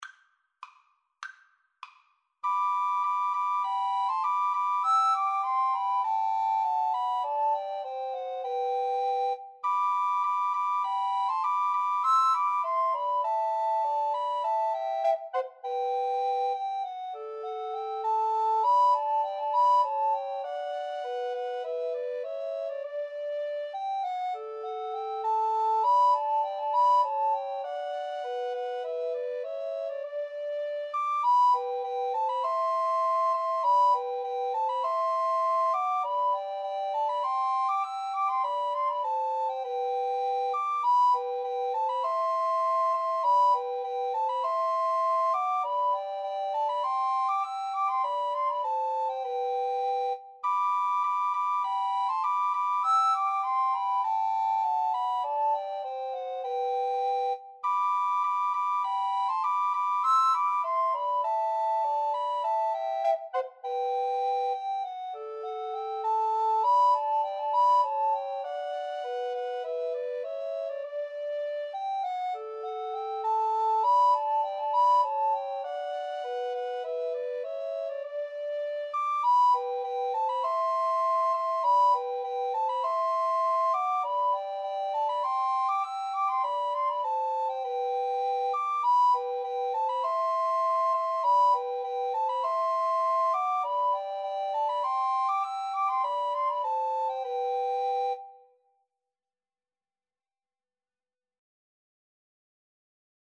Free Sheet music for Recorder Trio
G major (Sounding Pitch) (View more G major Music for Recorder Trio )
2/2 (View more 2/2 Music)
Traditional (View more Traditional Recorder Trio Music)